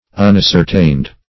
unascertained - definition of unascertained - synonyms, pronunciation, spelling from Free Dictionary